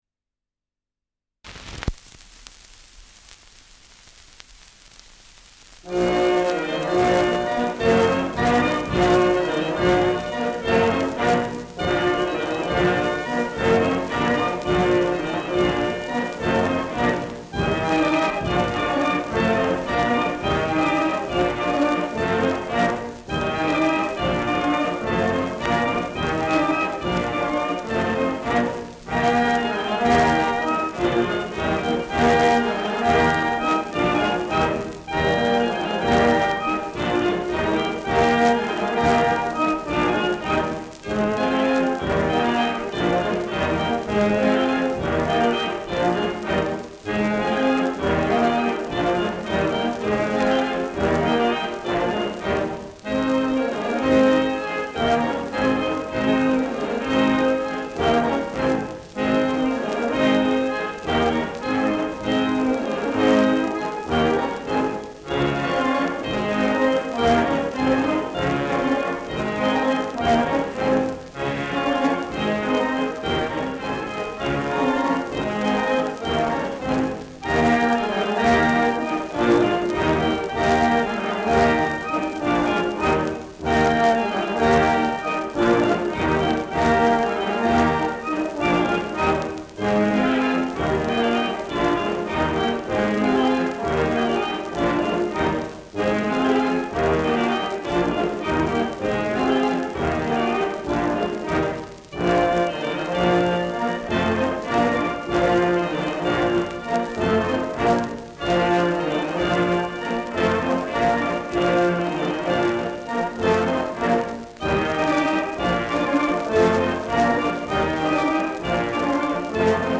Schellackplatte
Leicht abgespielt : Rauschen an lauten Stellen
[München] (Aufnahmeort)